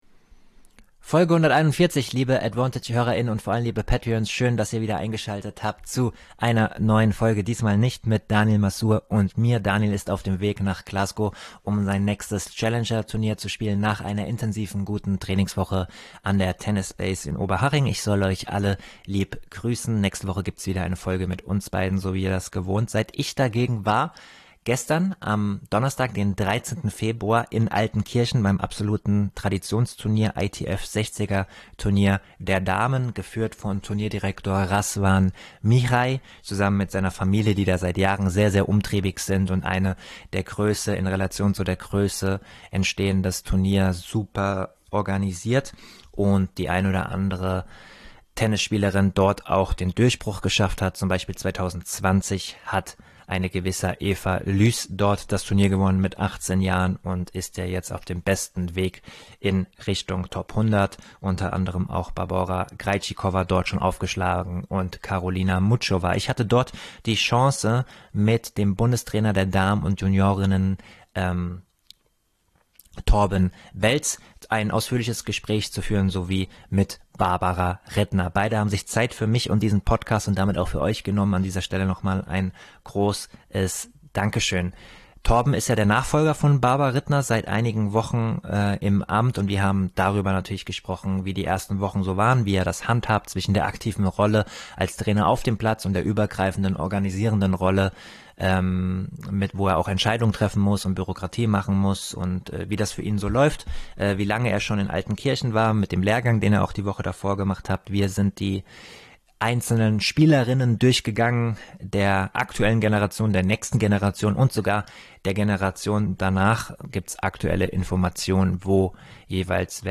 # Interviews